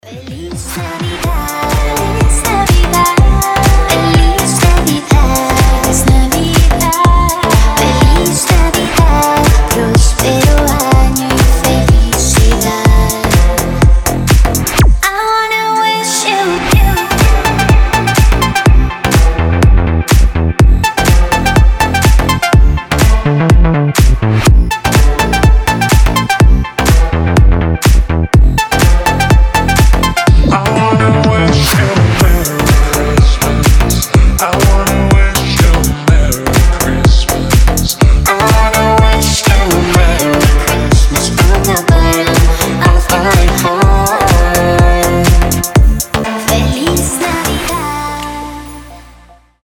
deep house
женский голос
рождественские
ремиксы